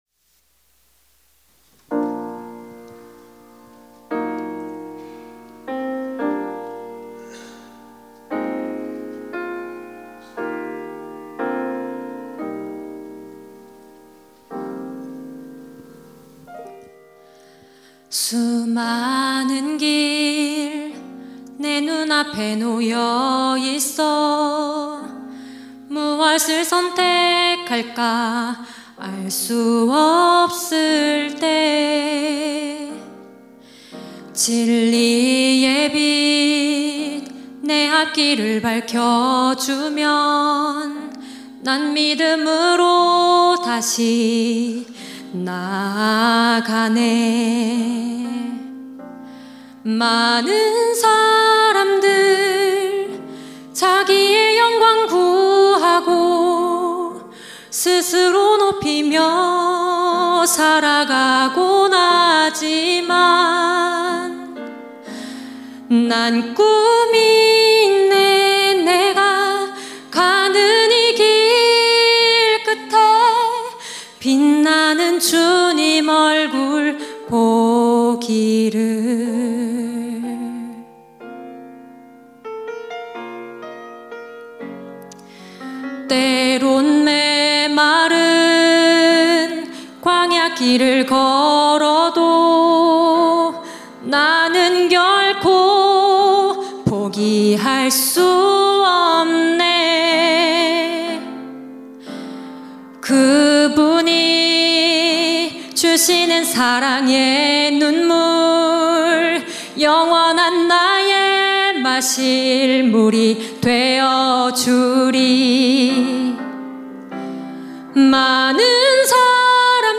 특송과 특주 - 길